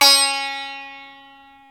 ETH XSITAR0E.wav